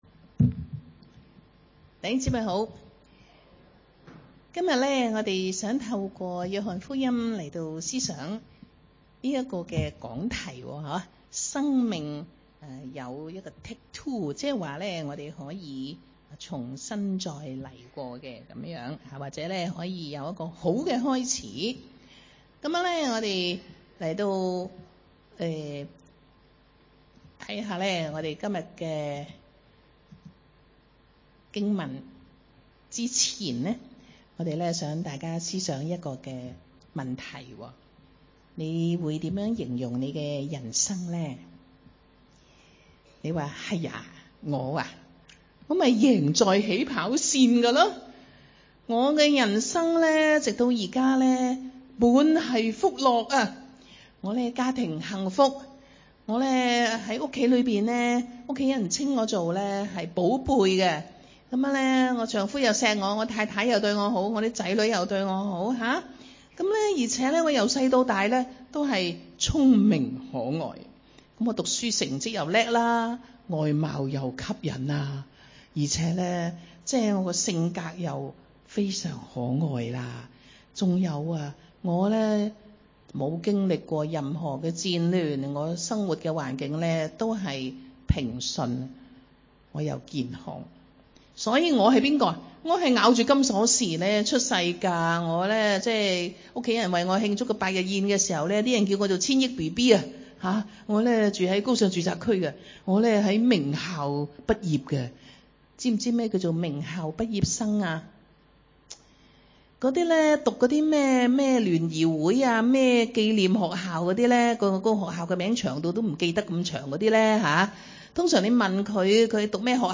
講道信息